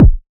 Kick
Original creative-commons licensed sounds for DJ's and music producers, recorded with high quality studio microphones.
Short Bass Drum G# Key 43.wav
fluffy-steel-kick-drum-g-sharp-key-51-0yE.wav